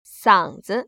[săng‧zi] 쌍쯔